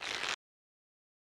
stretch1_UI.mp3